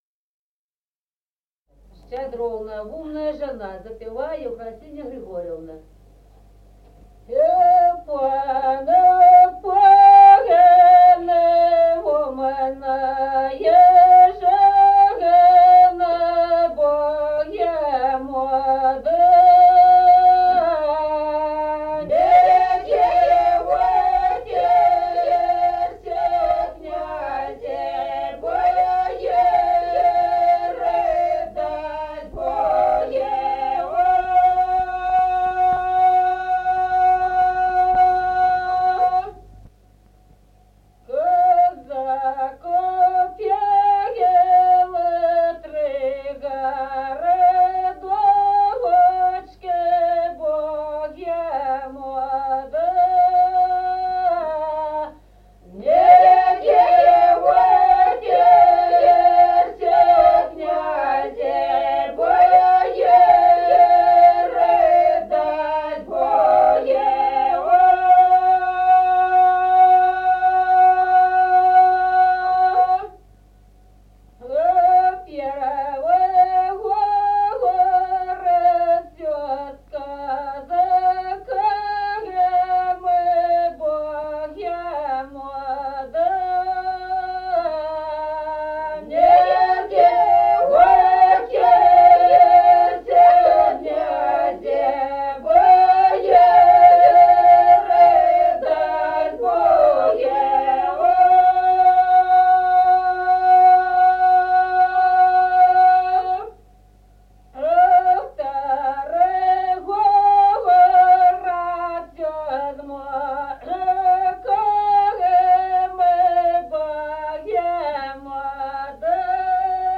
Народные песни Стародубского района «У пана, пана», новогодняя щедровная.
запев
подголосник
с. Остроглядово.